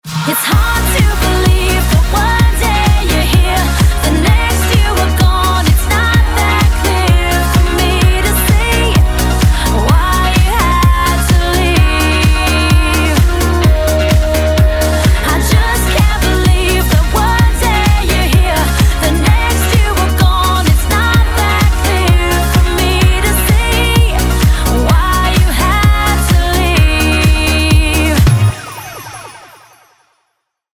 • Качество: 256, Stereo
dance
танцевальная музыка